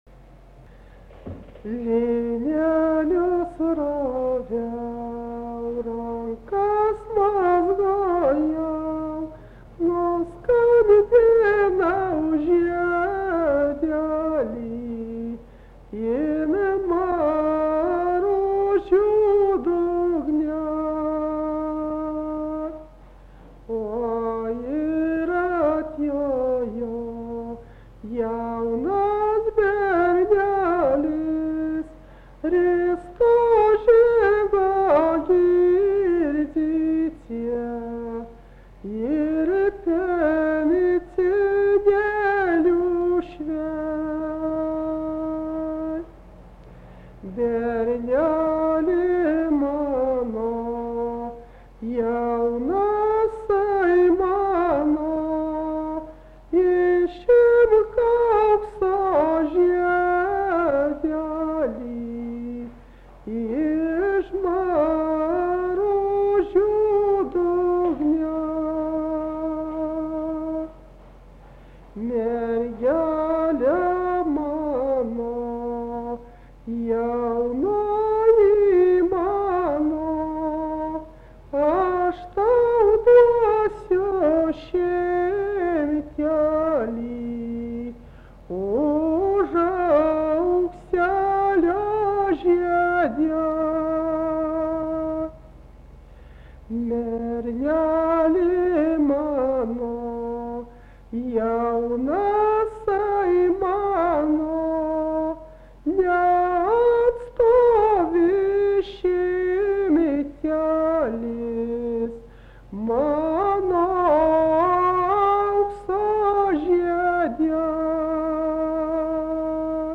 daina, kalendorinių apeigų ir darbo